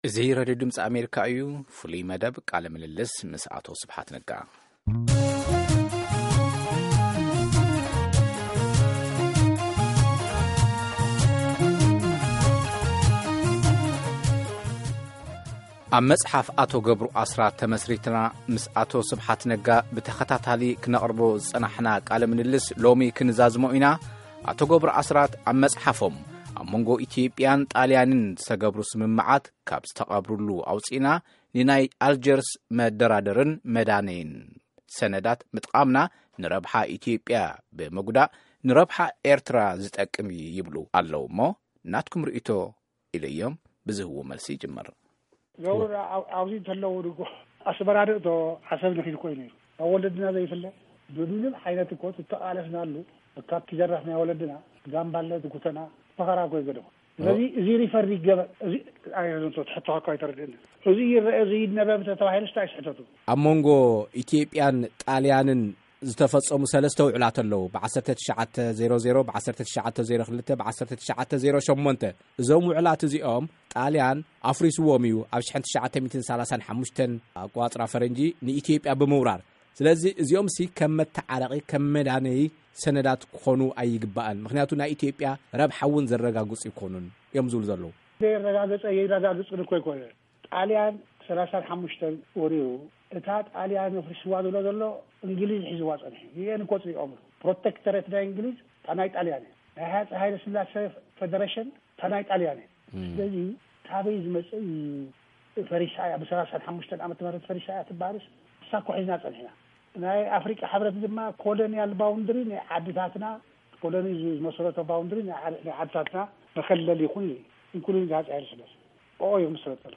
ቃለ ምልልስ ምስ ኣቦይ ስብሓት ነጋ 3ይ ኽፋል